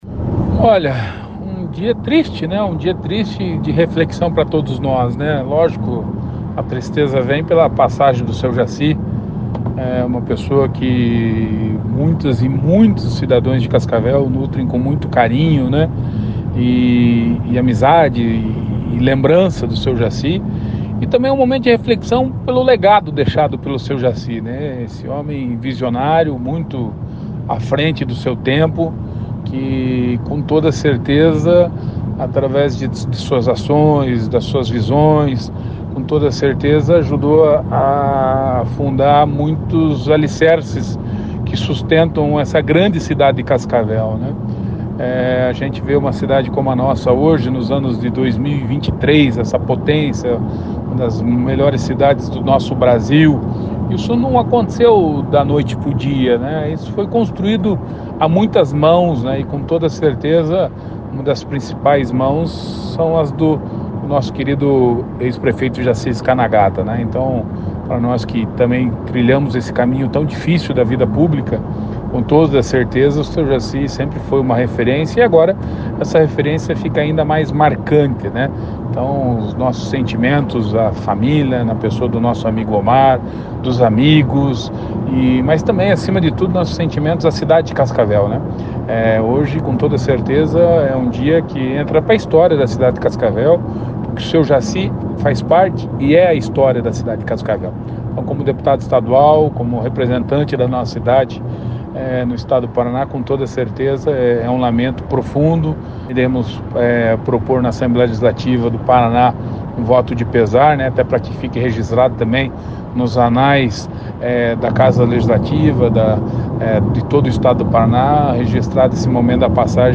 Player Ouça GUGU BUENO, DEPUTADO ESTADUAL